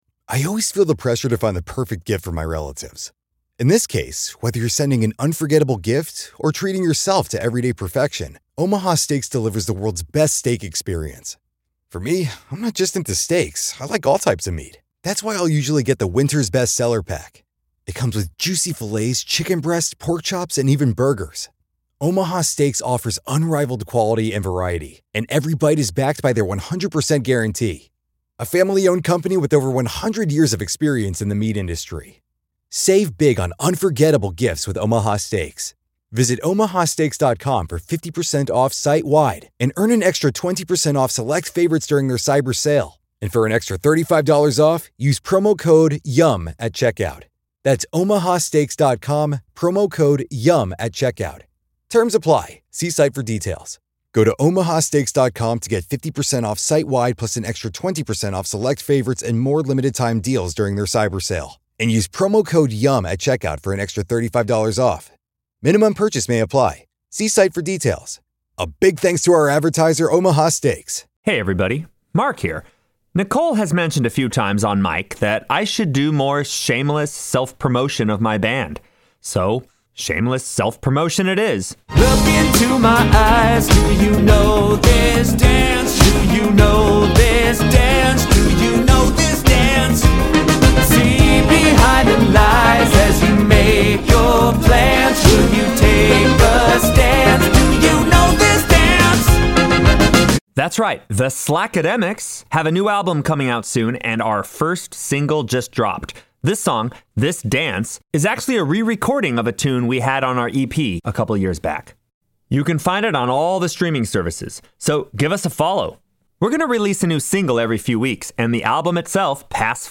The podcast about the songs you love and why you love them! Join us each week as two friends, one a music professor and one a psychology professor, hang out and deep dive about one iconic song. Laugh and learn with us about songwriting, the brain, and pop culture.